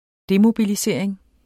Udtale [ ˈdemobiliˌseɐ̯ˀeŋ ]